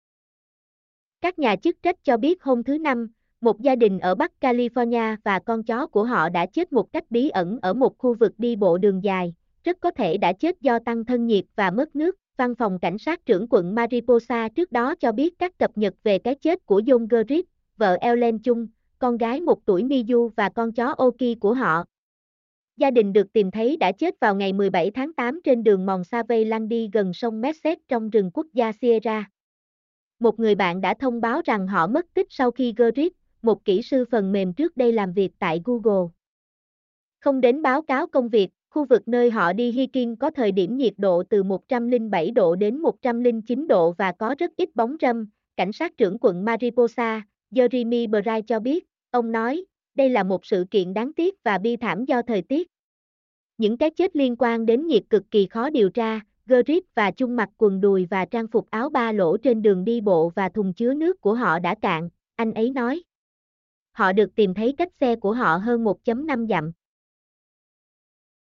mp3-output-ttsfreedotcom-8.mp3